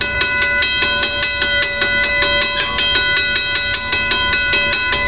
• RAILROAD CROSSING BELL.wav
RAILROAD_CROSSING_BELL_vIF.wav